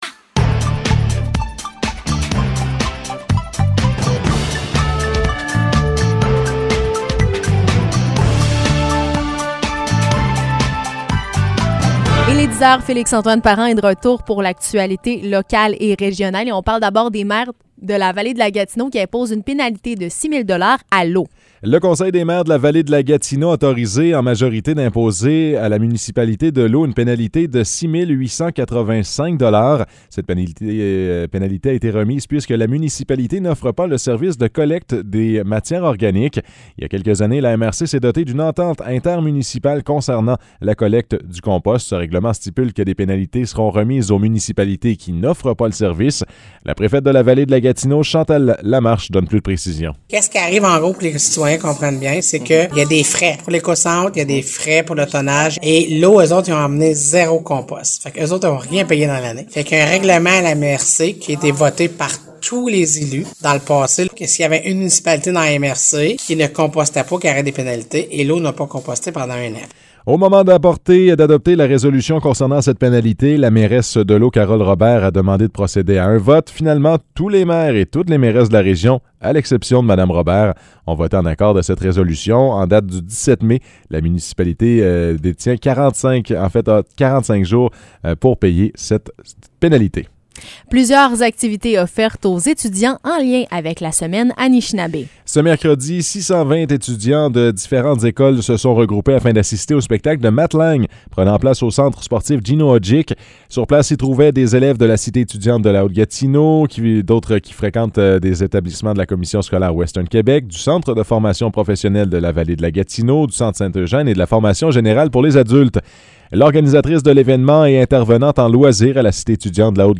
Nouvelles locales - 20 mai 2022 - 10 h